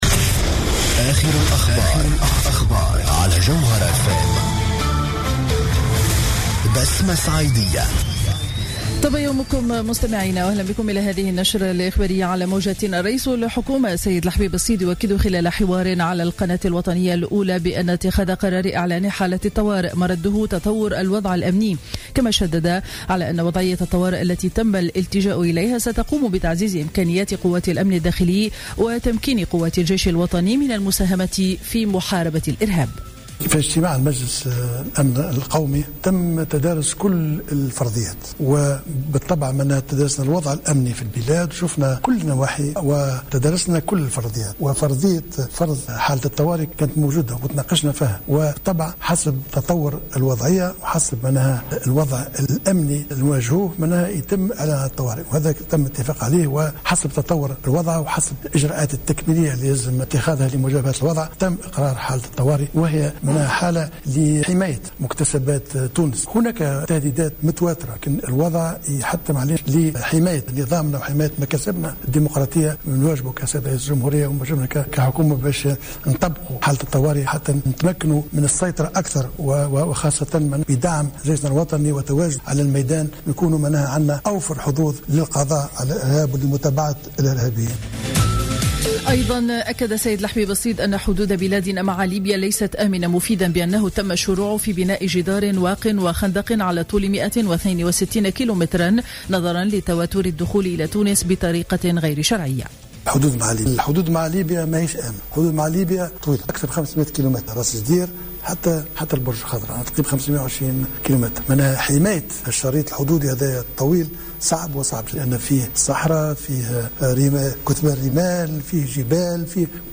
نشرة أخبار السابعة صباحا ليوم الأربعاء 08 جويلية 2015